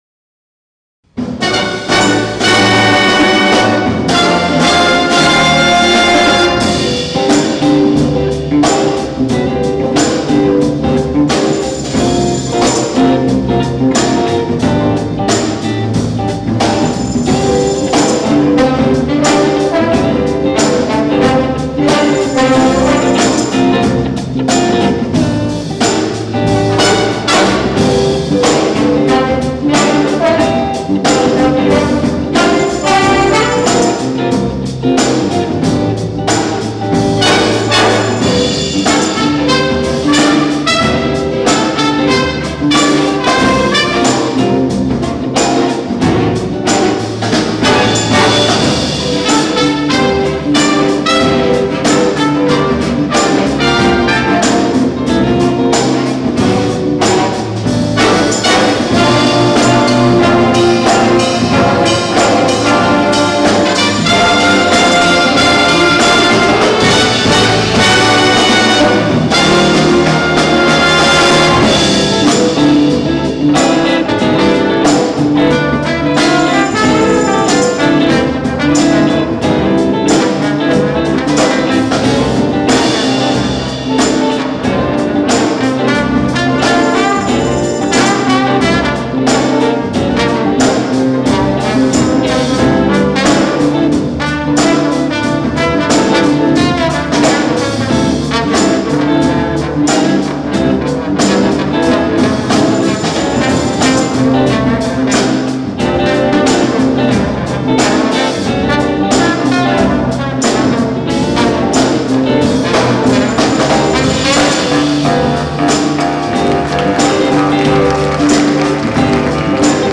Jazz Concert 2023 Recordings